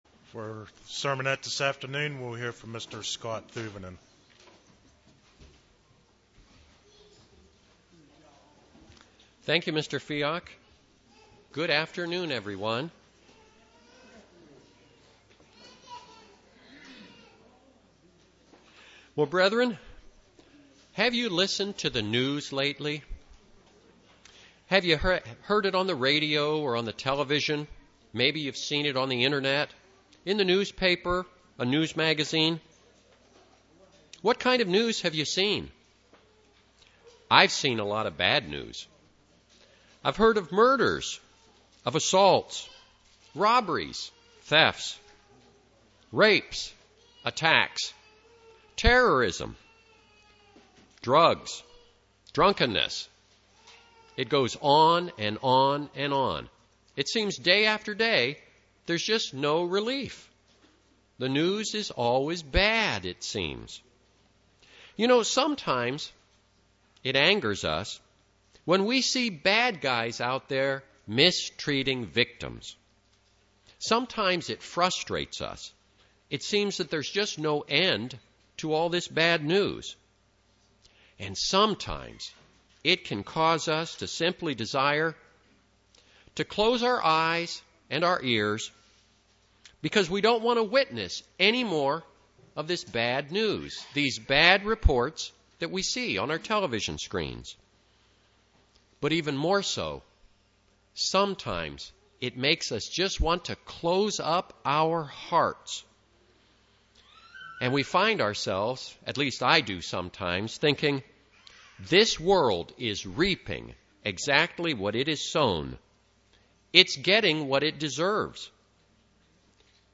Sermonette, Don't Let Your Heart Become Calloused